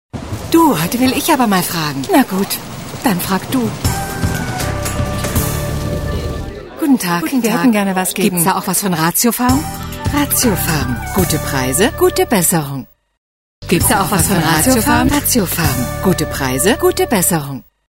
Warme Alt-Stimme, Kompetenz in der Stimme. Reife Stimme.
Sprechprobe: Werbung (Muttersprache):
German female voice over talent